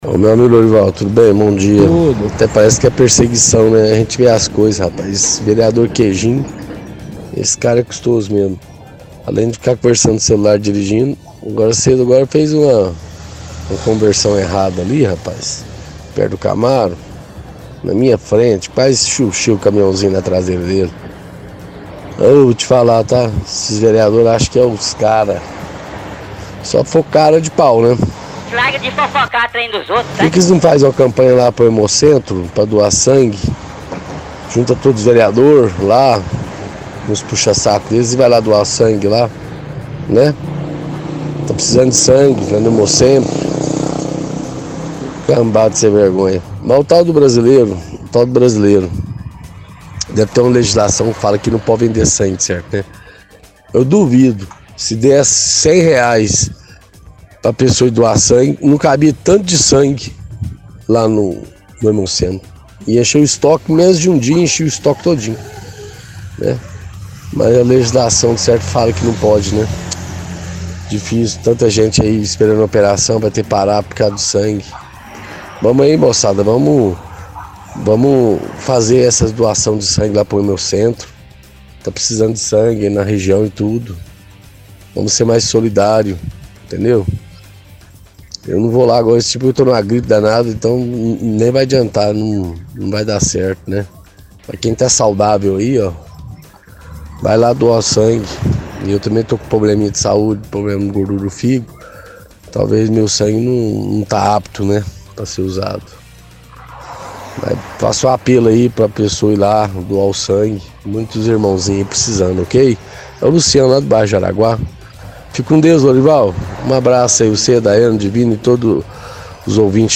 – Ouvinte fala sobre vereador Queijim, questiona o por que não fazem campanha para o hemocentro pela falta de estoque de sangue. Sugere que ação seja remunerada, assim, incentivaria mais a população a doar sangue. Pede que pessoas sejam mais solidárias e ajudem a reabastecer o estoque.